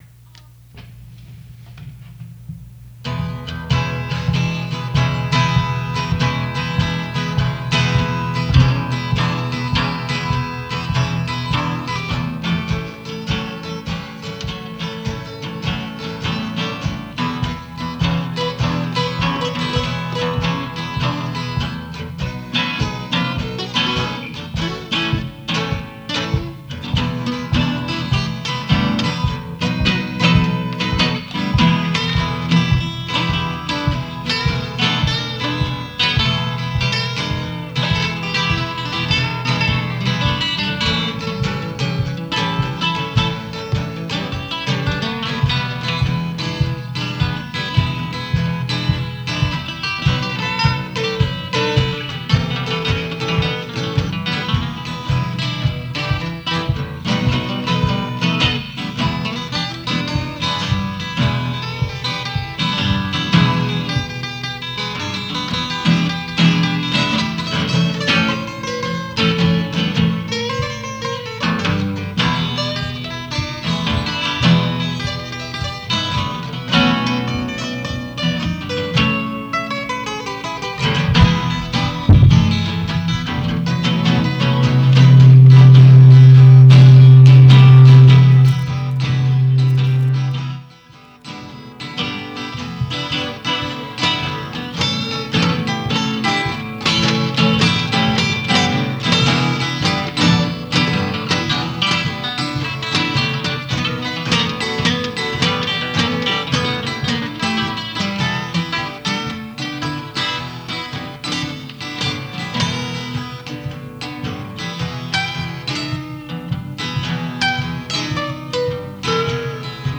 The ’69 broadcast ended with a live (and somewhat over-extended) blues jam, with both Kirkland and Hamilton guitarists going at it to the best of their varying abilities!
07_h_k_guitar_jam.m4a